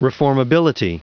Prononciation du mot reformability en anglais (fichier audio)
Prononciation du mot : reformability